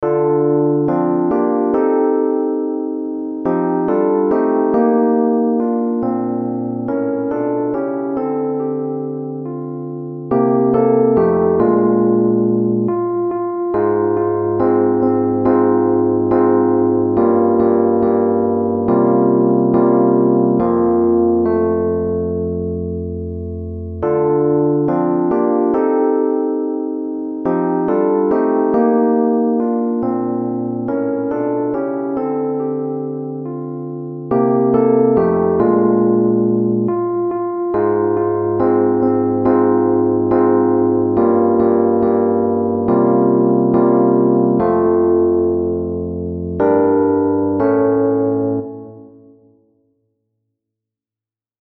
Informed by the encouragement in many psalms to ‘sing a new song’, and in honour of the fiftieth anniversary of the foundation of the South Woden Uniting Church to be celebrated on 22 October 2017 at Pearce in the Australian Capital Territory, a new setting has been written for the occasion.